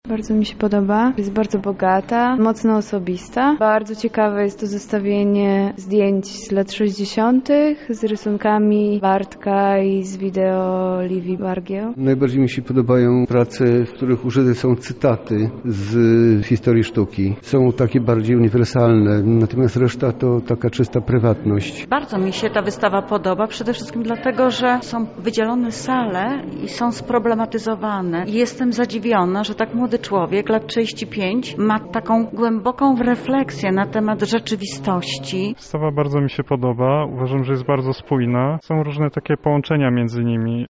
Zapytaliśmy uczestników o wrażenia w trakcie pokazu.
relacja